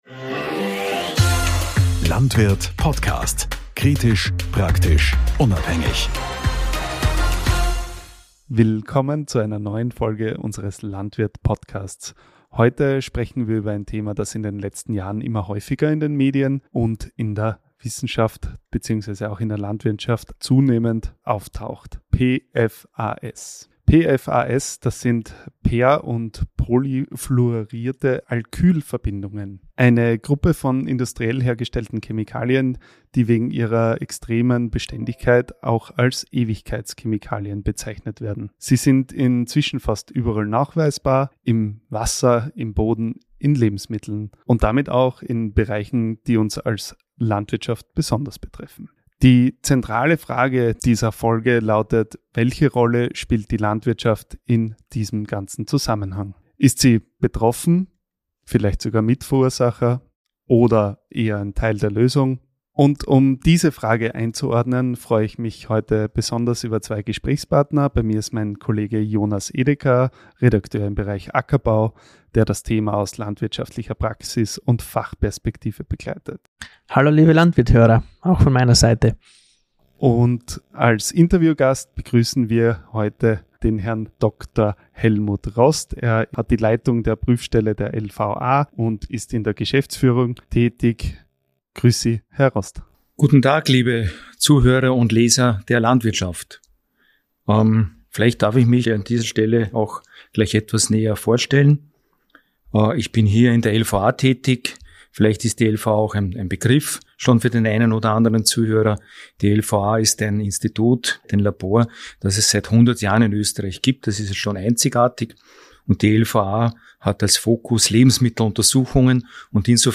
Warum nicht alle diese Ewigkeitschemikalien aus der Landwirtschaft stammen, Bauern aber dennoch eine besondere Verantwortung tragen klären wir in einem ausführlichen Interview...